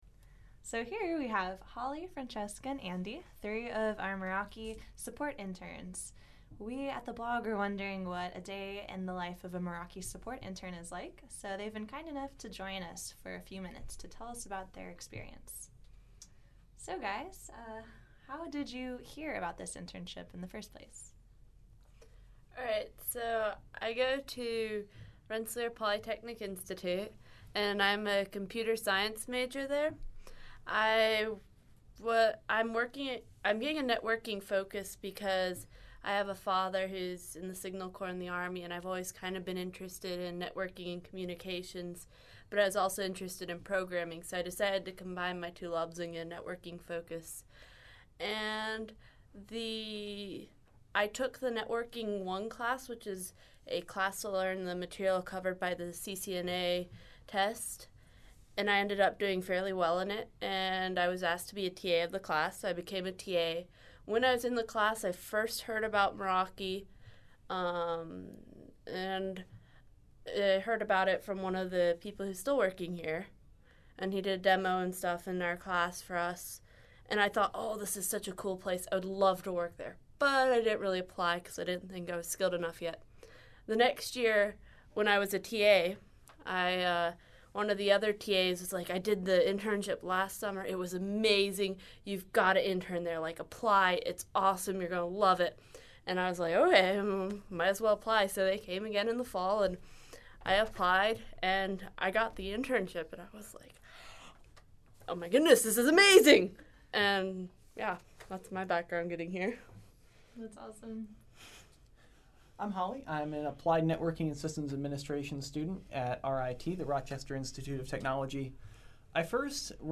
support_intern_interview_part_1.mp3